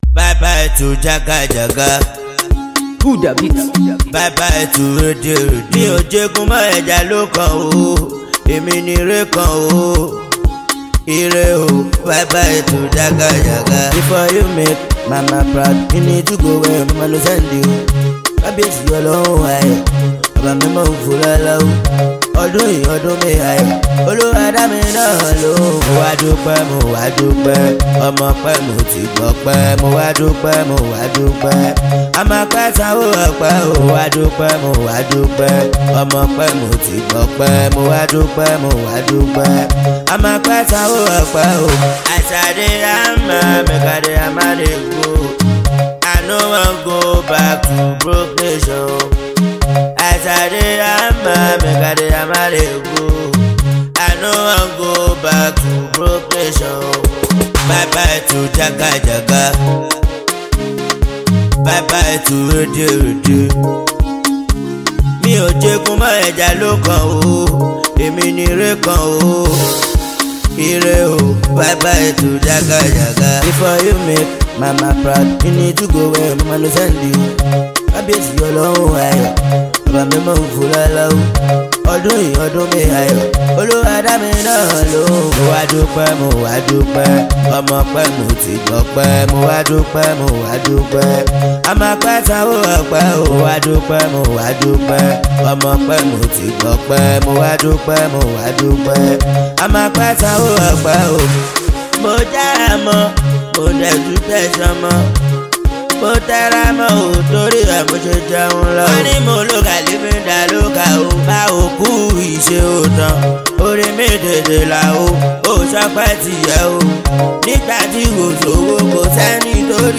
Nigerian / African Music
Genre: Afrobeats